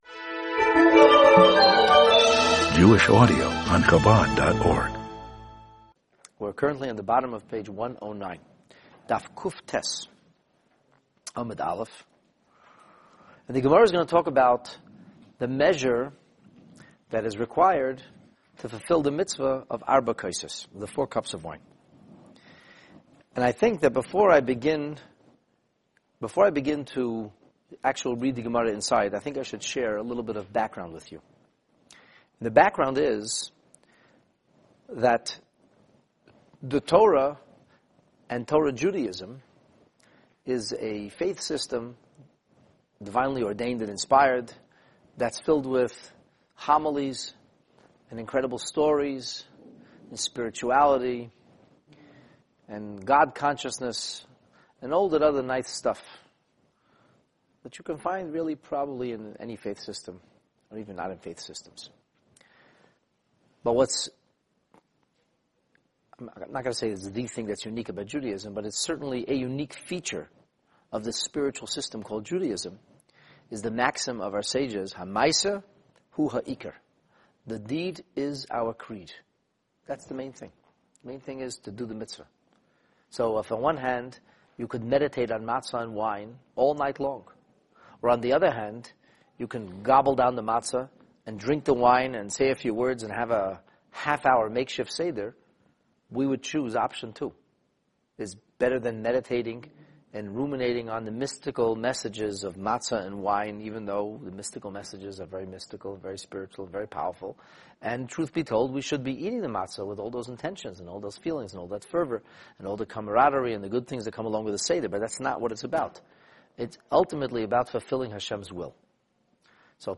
The Talmud on the Pesach Seder, Lesson 6 (Daf/Page 109a) This class provides a comprehensive overview of the notion of Halachic sizes and measures comprising the specific parameters of the fulfillment of many Mitzvahs. This discussion serves as an intro to understanding the Talmud’s analysis of the required volume and other technical details – all related to properly drinking the proverbial four glasses of wine at the Seder. This session ultimately concludes with pragmatic instructions and practical application of these Torah theorems.